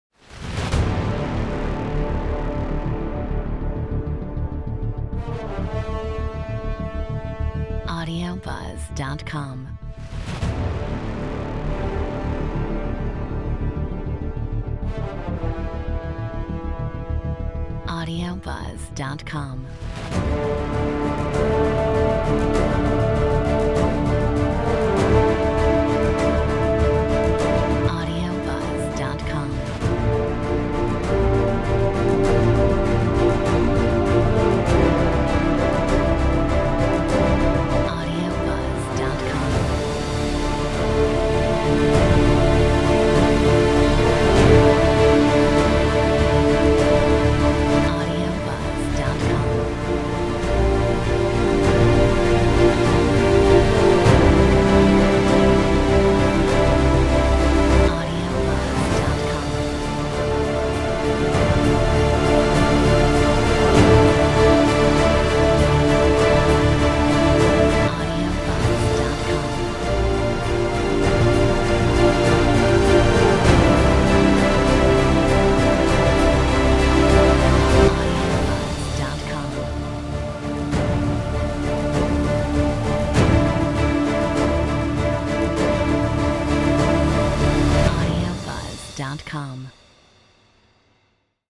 Metronome 99